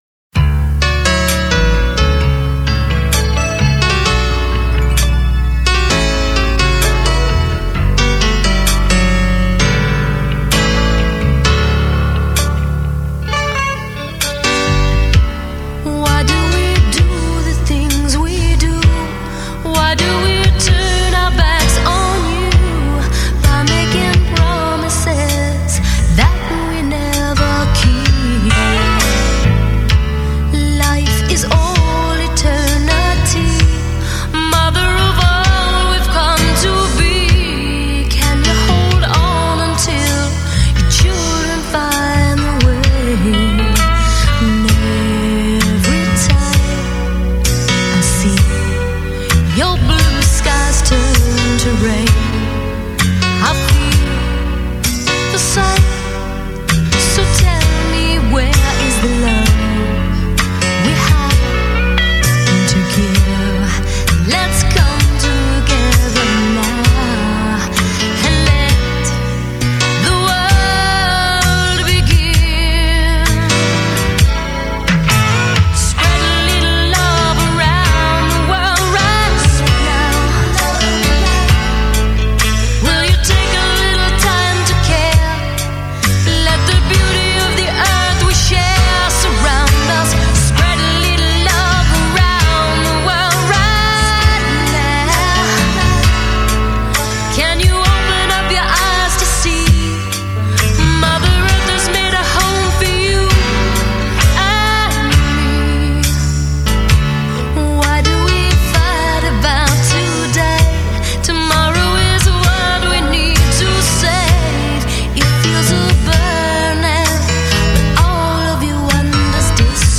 all instruments and drum programming